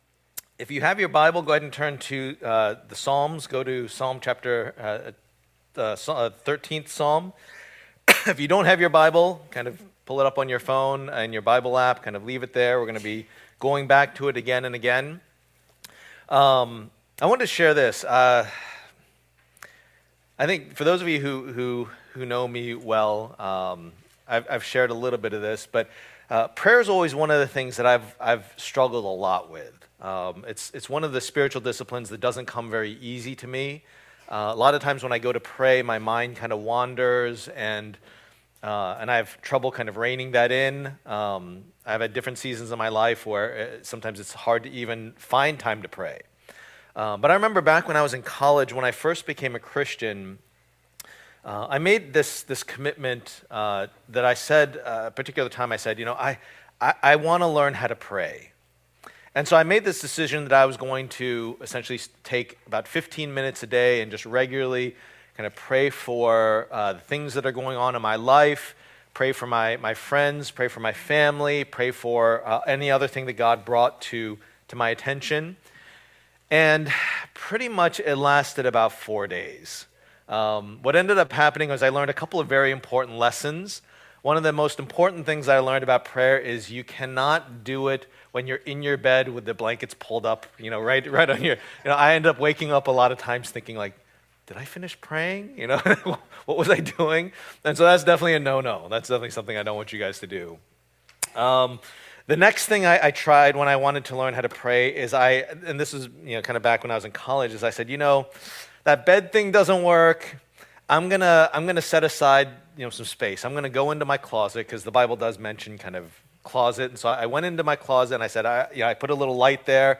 Passage: Psalm 13:1-6 Service Type: Lord's Day